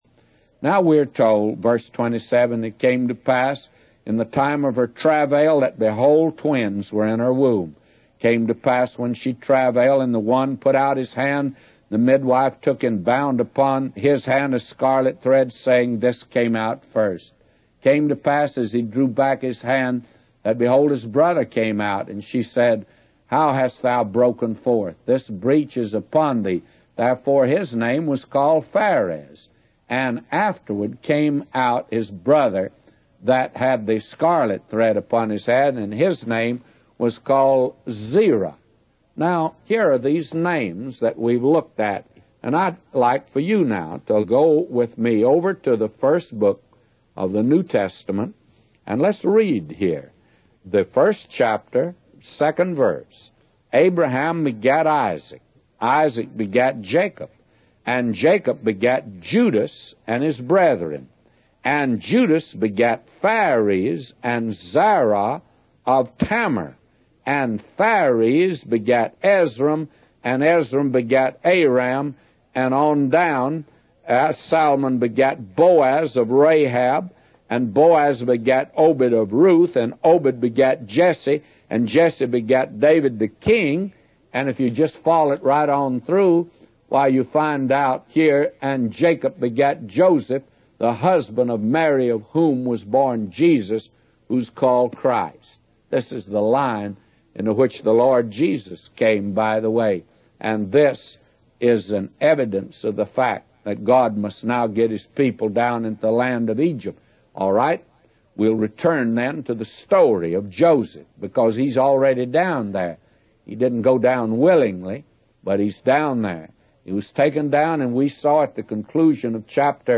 A Commentary By J Vernon MCgee For Genesis 38:27-999